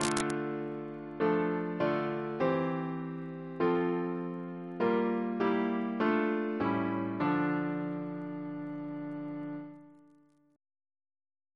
CCP: Chant sampler
Single chant in D minor Composer: Thomas S. Dupuis (1733-1796), Organist and Composer to the Chapel Royal Reference psalters: ACB: 332